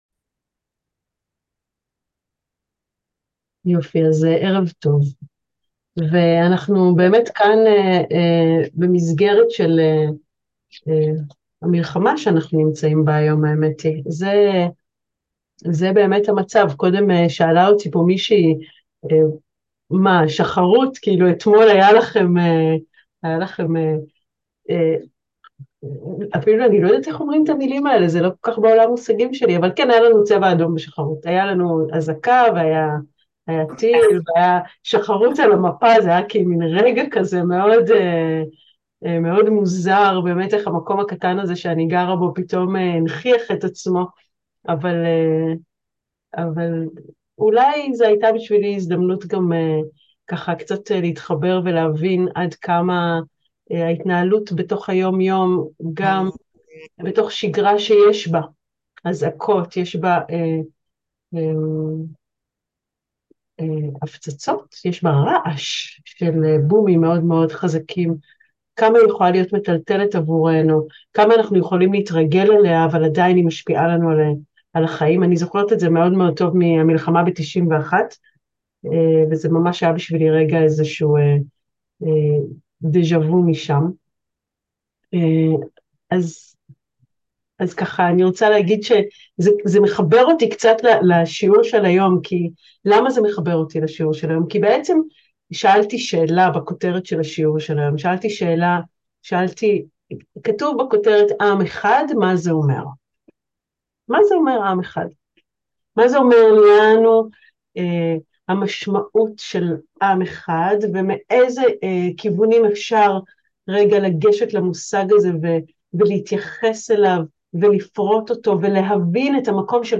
מהו עם? מהי ההשתייכות ומה משמעותנ? שיעור הכרתי קצר